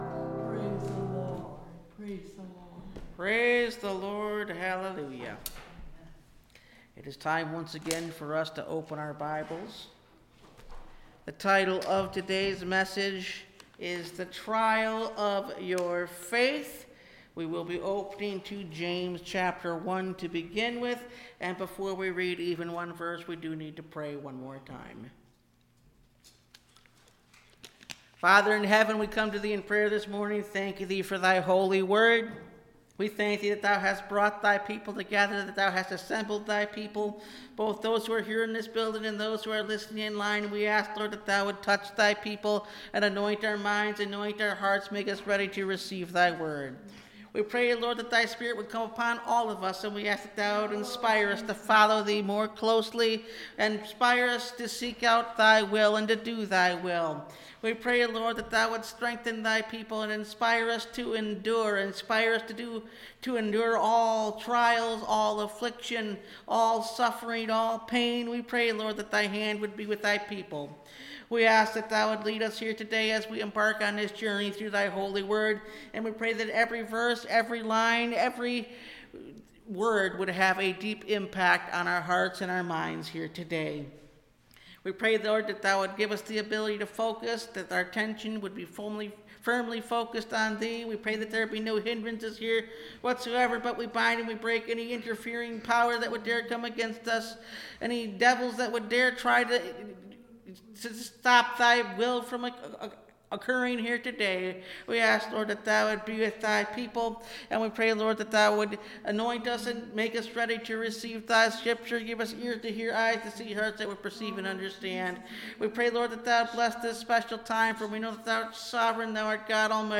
The Trial Of Your Faith (Message Audio) – Last Trumpet Ministries – Truth Tabernacle – Sermon Library